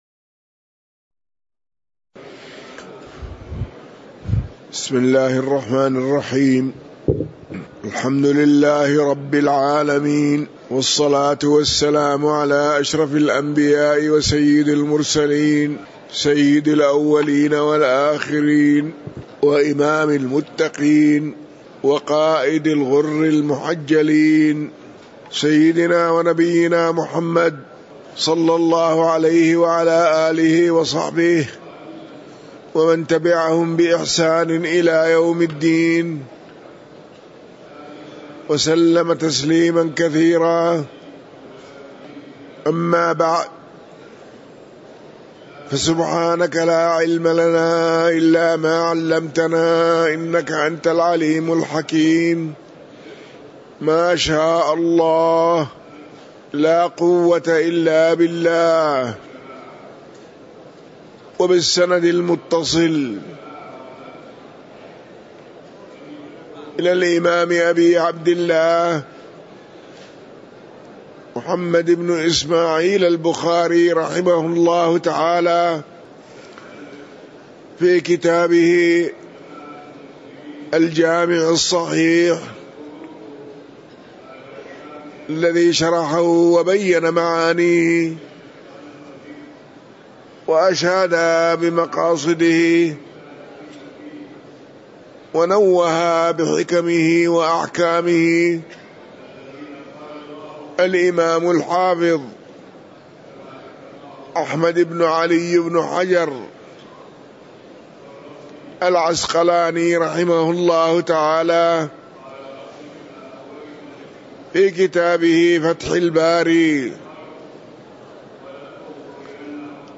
تاريخ النشر ٢٨ رجب ١٤٤٤ هـ المكان: المسجد النبوي الشيخ